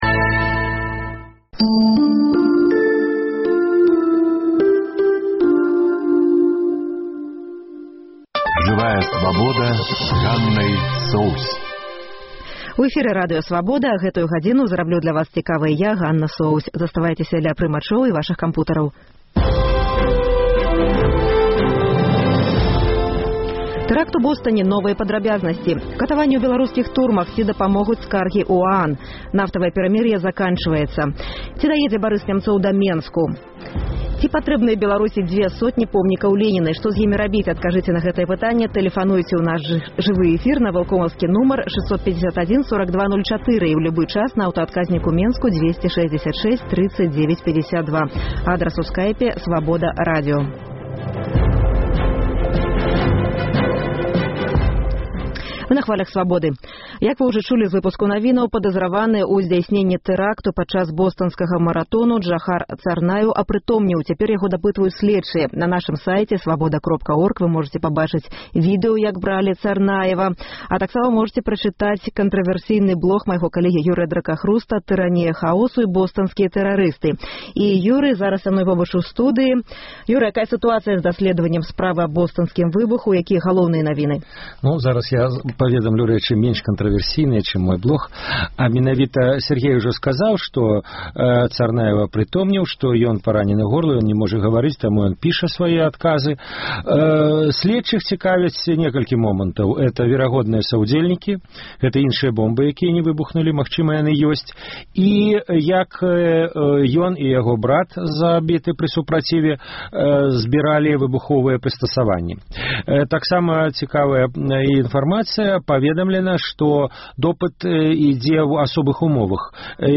Рэпартаж з заводу «Брэстгазаапарат», дзе рабочых прымусова адпраўляюць у адпачынкі.